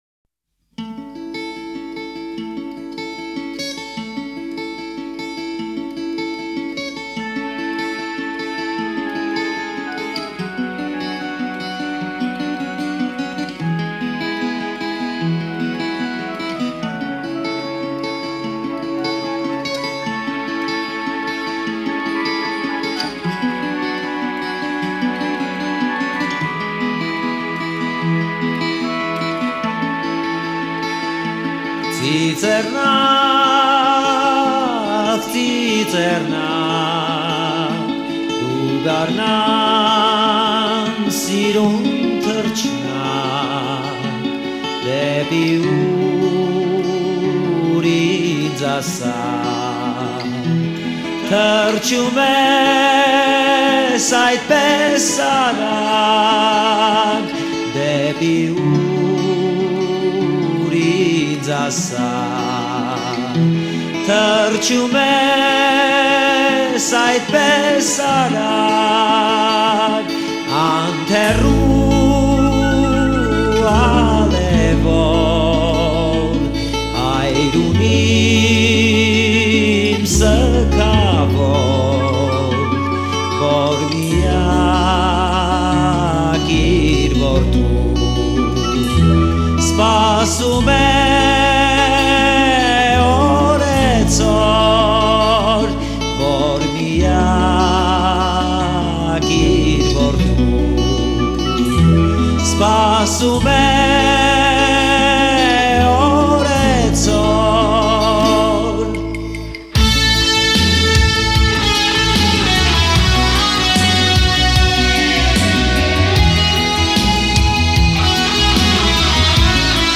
дудук + скрипка
Какое богатство звука!